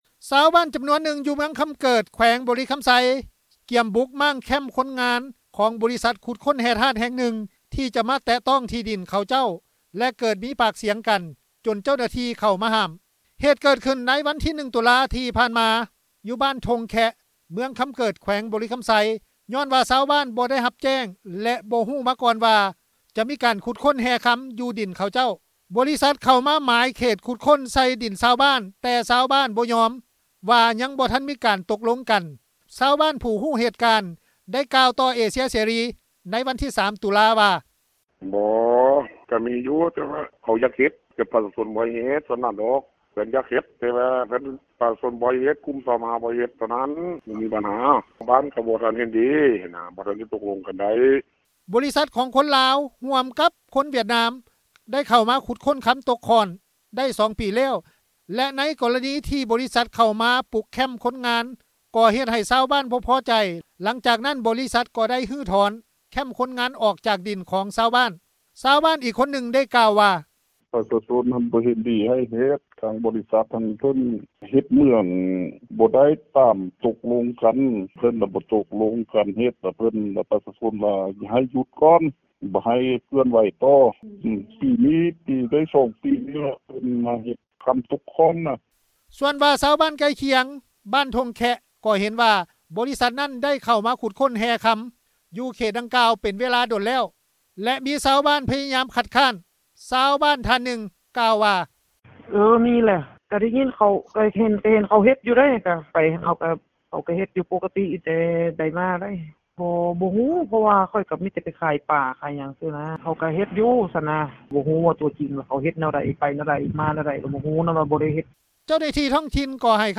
ຊາວບ້ານອີກທ່ານນຶ່ງ ໄດ້ກ່າວວ່າ:
ຊາວບ້ານທ່ານນຶ່ງ ກ່າວວ່າ:
ເຈົ້າໜ້າທີ່ທ້ອງຖິ່ນ ທ່ານນີ້ ກ່າວວ່າ: